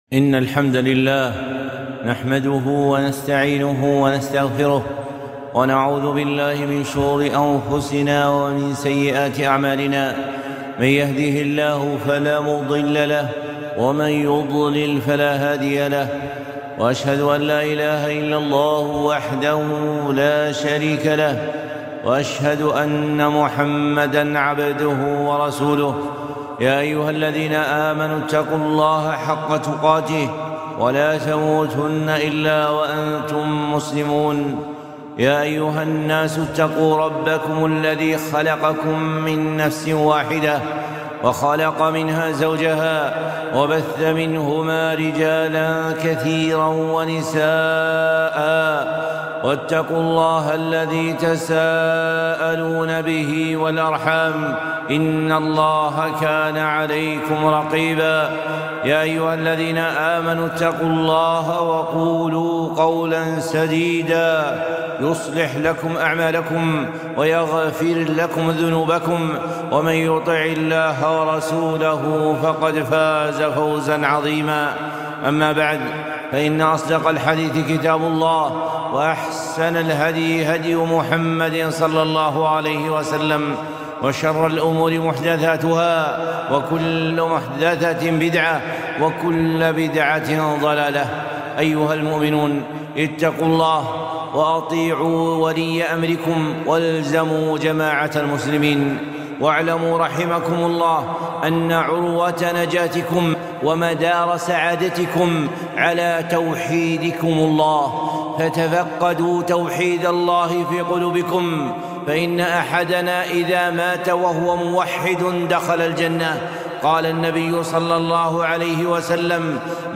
خطبة - تفقدوا توحيدكم